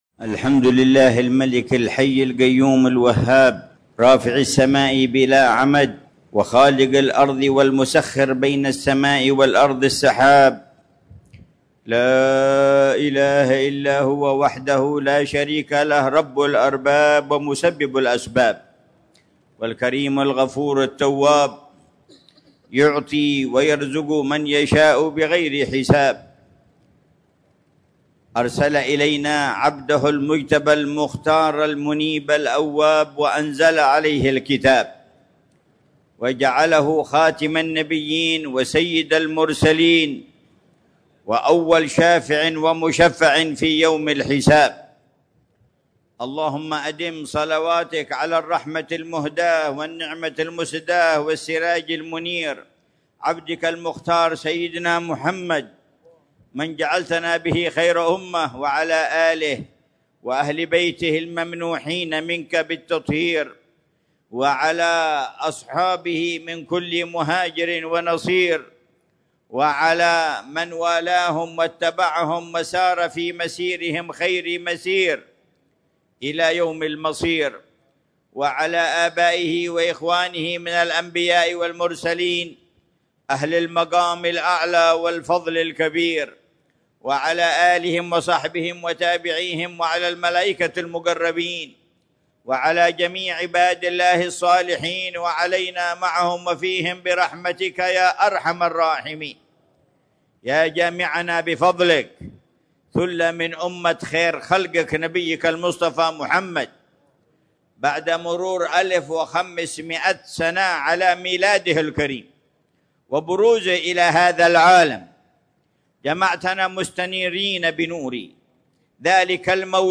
مذاكرة العلامة الحبيب عمر بن محمد بن حفيظ في المولد السنوي في مسجد الإمام محمد بن علي مولى عيديد، بمدينة تريم، ليلة الأحد 29 ربيع الأول 1447هـ بعنوان: